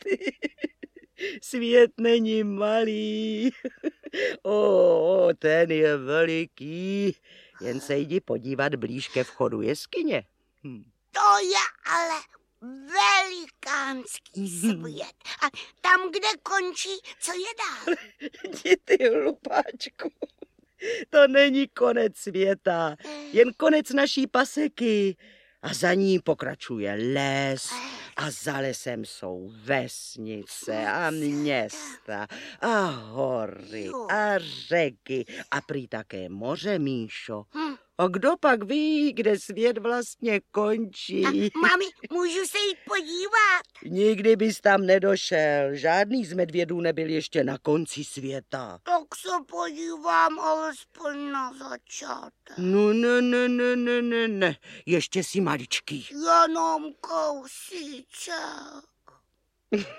Audiobook
Read: Antonie Hegerlíková